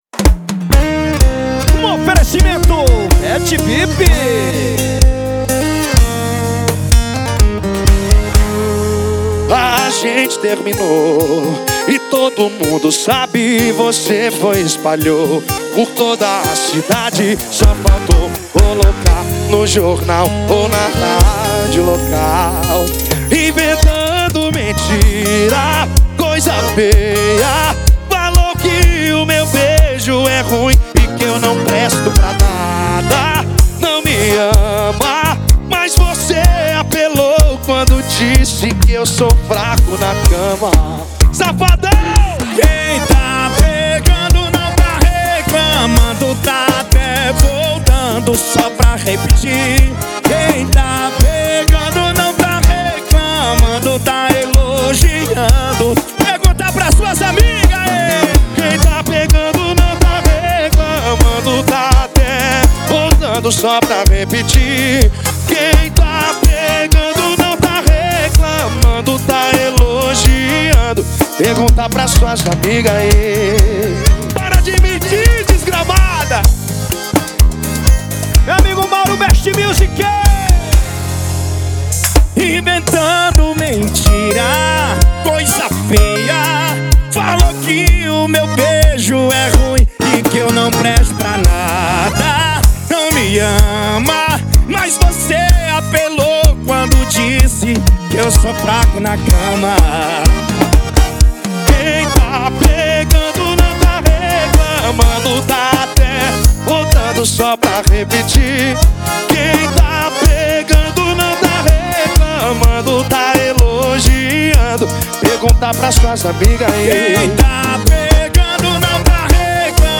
2024-02-14 22:40:15 Gênero: Forró Views